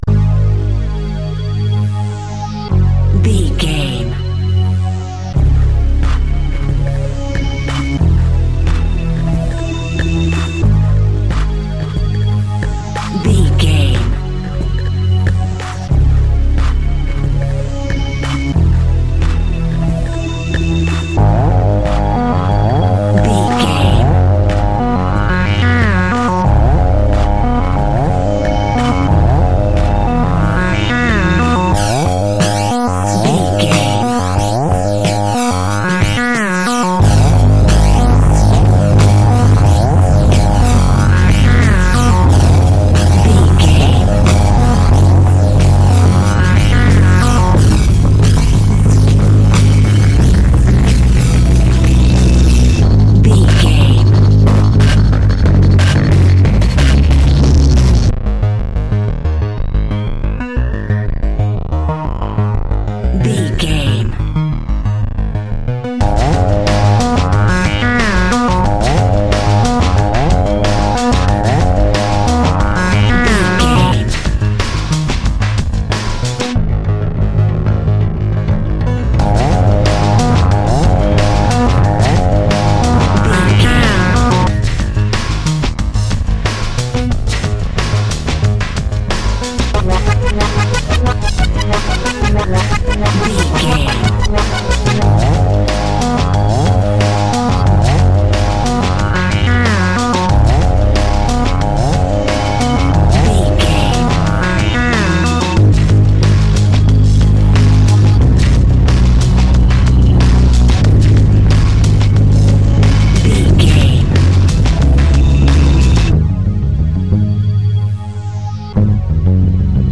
Garage Music Cue.
Aeolian/Minor
A♭
frantic
driving
energetic
hypnotic
industrial
dark
drum machine
synthesiser
synth leads
synth bass